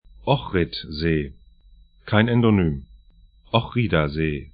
Pronunciation
Ochridasee   'ɔxrɪt-ze: